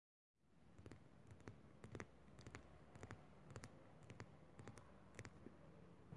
Tag: 环境 atmophere 记录